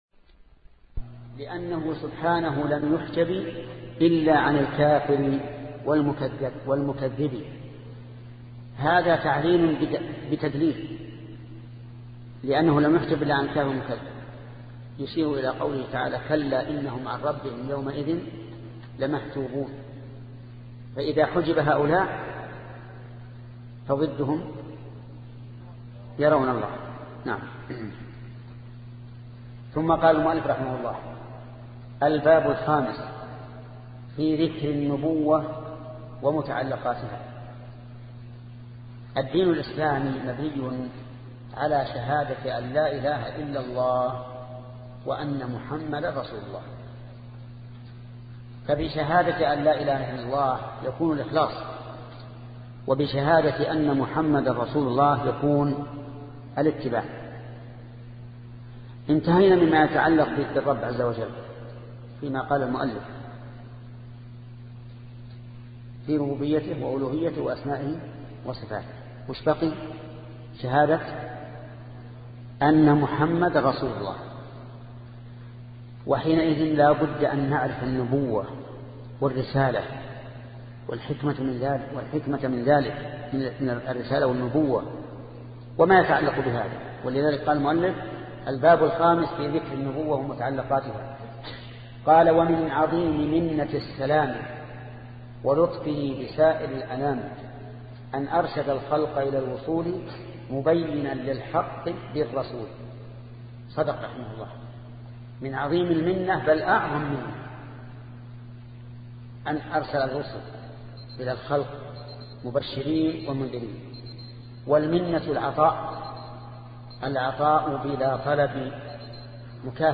سلسلة مجموعة محاضرات شرح العقيدة السفارينية لشيخ محمد بن صالح العثيمين رحمة الله تعالى